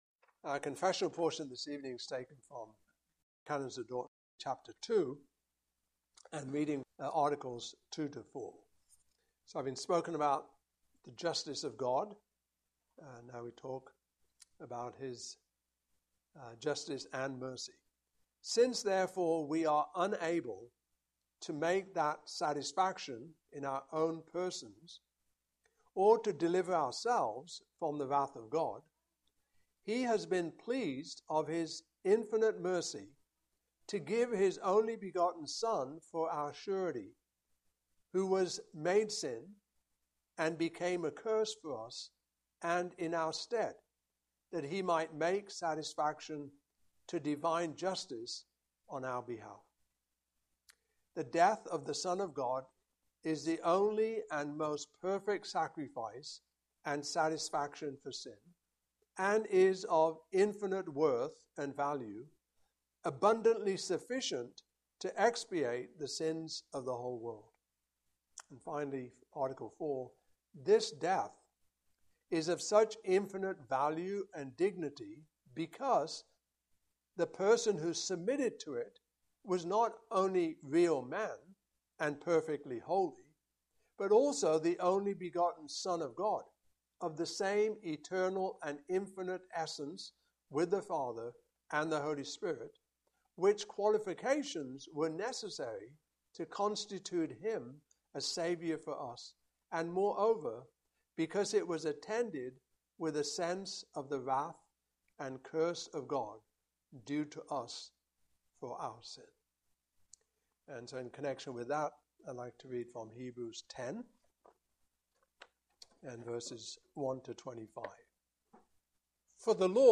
Canons of Dordt 2025 Passage: Hebrews 10:1-25 Service Type: Evening Service Topics